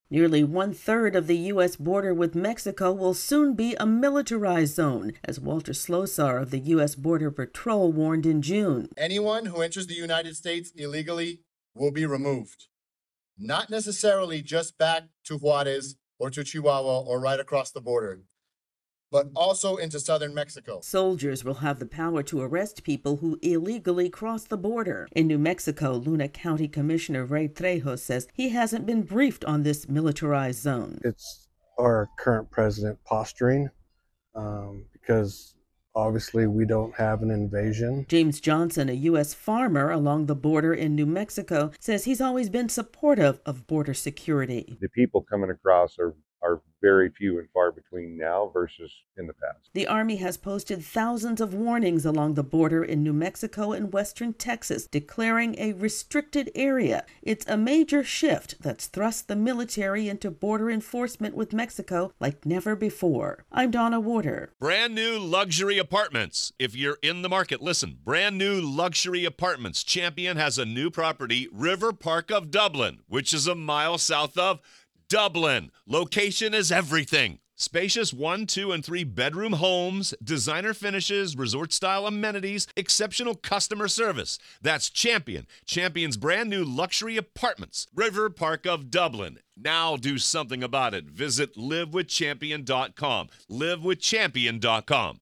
Much of the U.S. - Mexico border is becoming a military restricted area, after President Donald Trump earlier this year declared the border a national emergency. AP correspondent